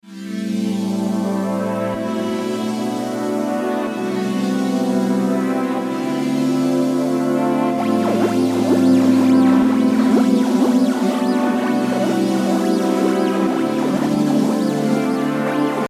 では、音色を変えて、アタックが緩やかな音色に使用するとどうなるんでしょうね？
失敗ではないと思うのですが、エフェクトの部分で言えば、LFOでいじっているフィルターの音しか聞こえてこない感じがして物足りない感じがしますね。